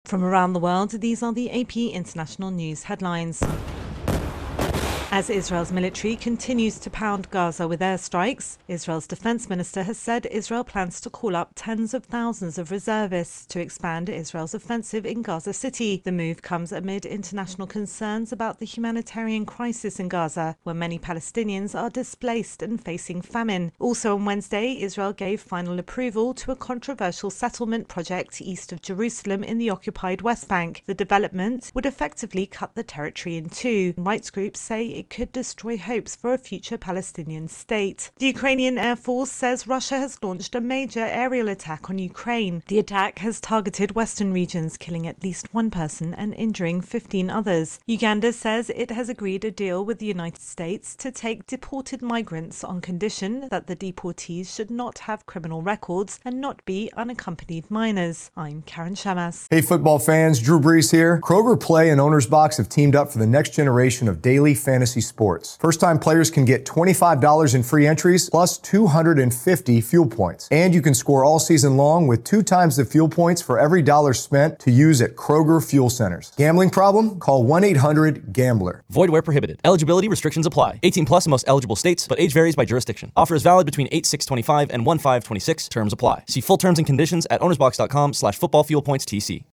The latest International News Headlines